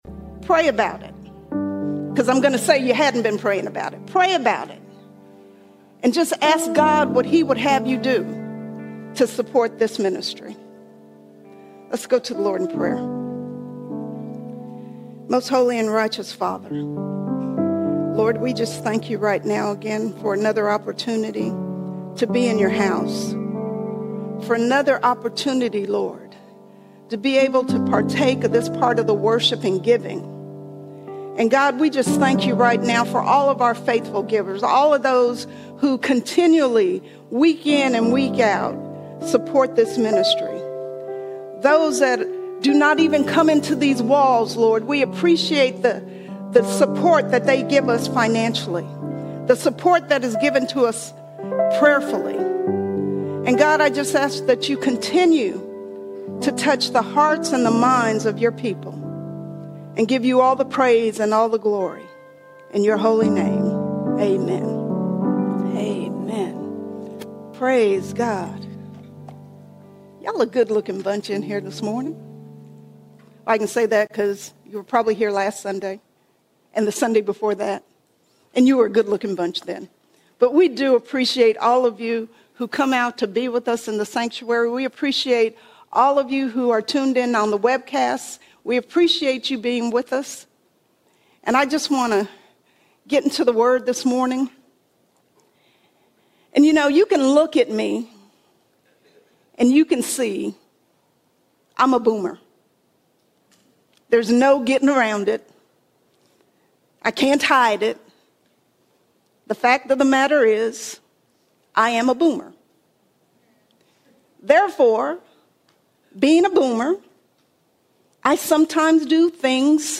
12 April 2026 Series: Sunday Sermons All Sermons Time To Represent Time To Represent Time to represent!